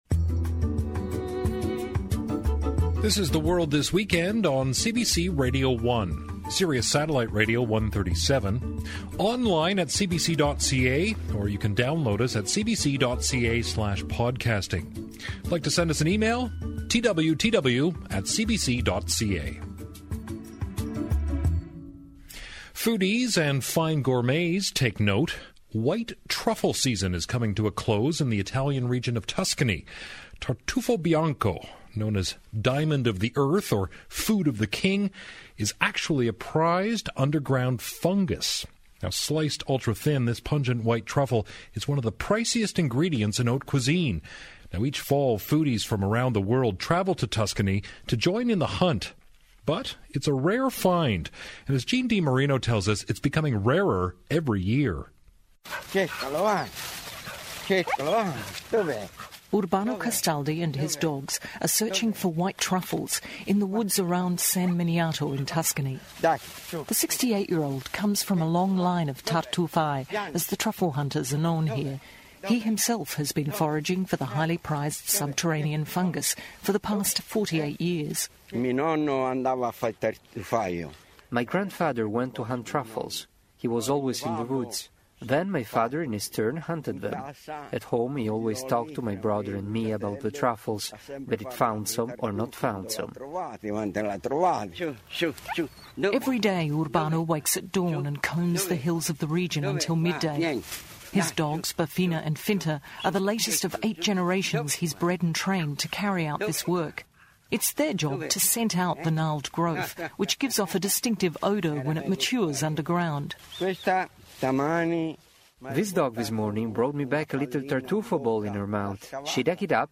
• Location: San Miniato, Italy
CBC Radio “The World this Weekend” feature story